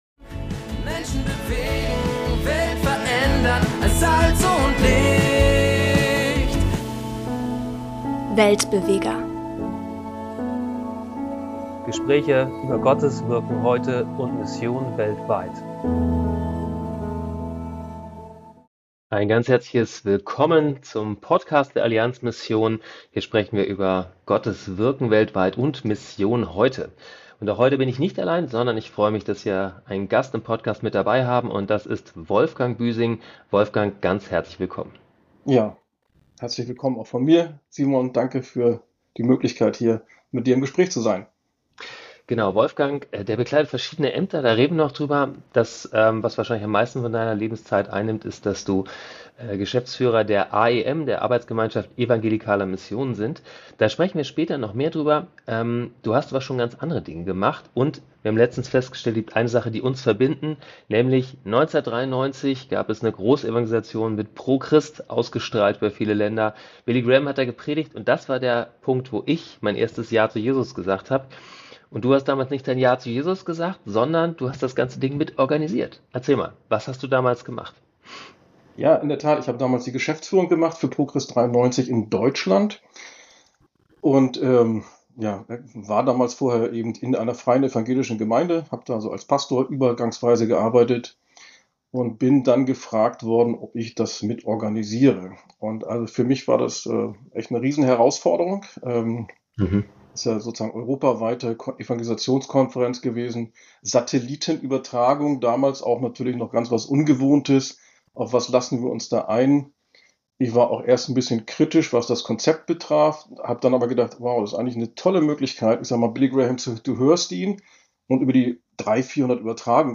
Es geht um Herausforderungen wie Migration, strukturelle Veränderungen in der Weltweiten Evangelischen Allianz , neue internationale Partnerschaften, aber auch die Frage: Wie kann die junge Generation für Mission begeistert werden? Ein ehrliches, inspirierendes Gespräch über Teamarbeit, Gottes Führung und die Kraft von mutigen Schritten in unbekannte Richtungen .